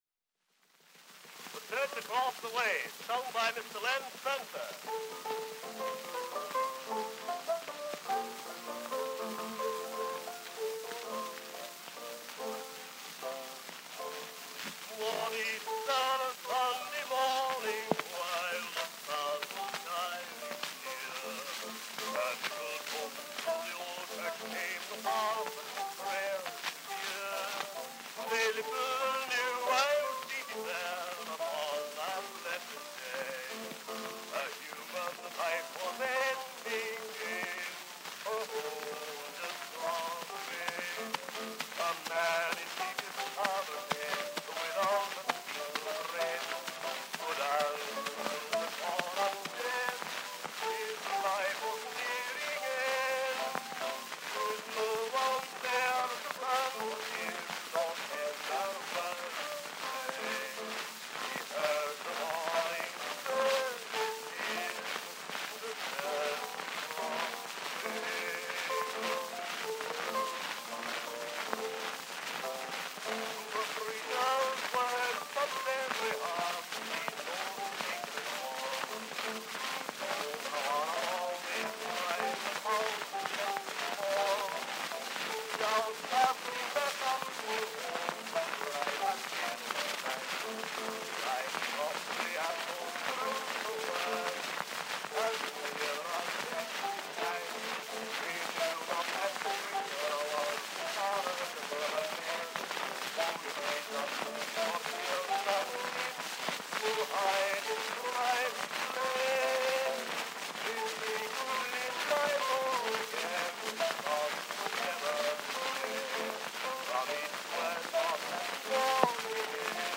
On brown wax cylinder.
Popular music